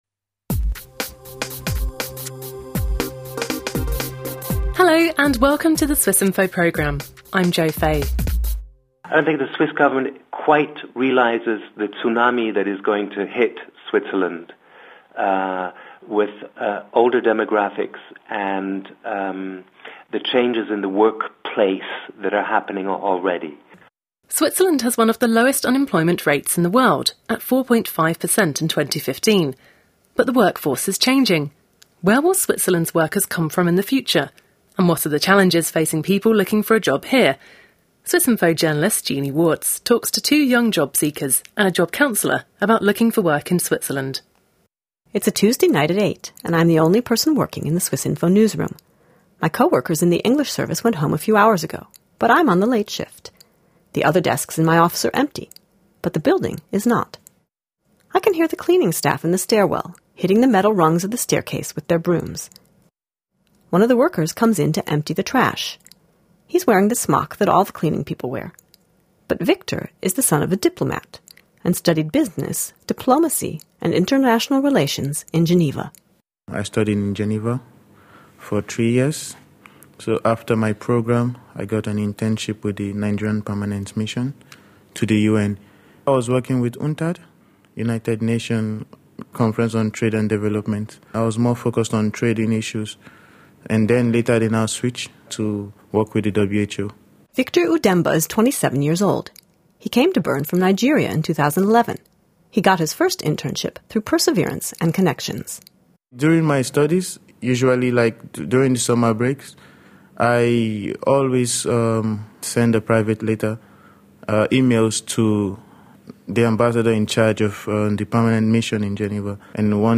talks with job seekers and a job counselor about the experience.